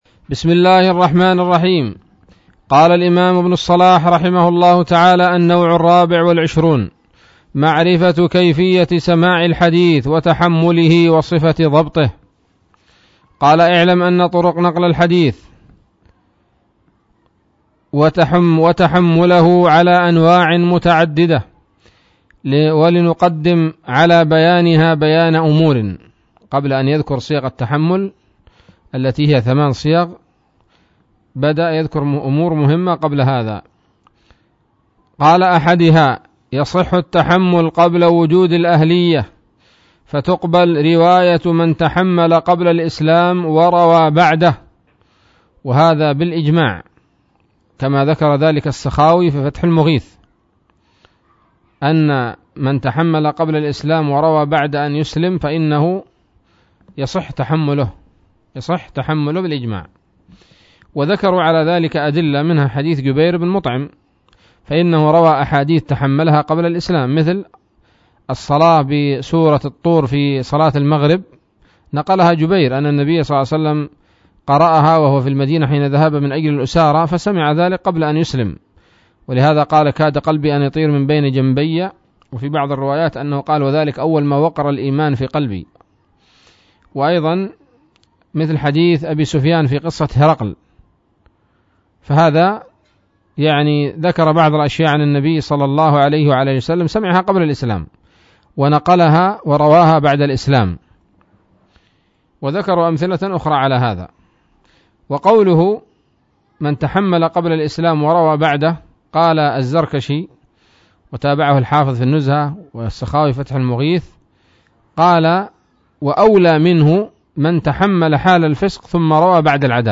الدرس الثامن والخمسون من مقدمة ابن الصلاح رحمه الله تعالى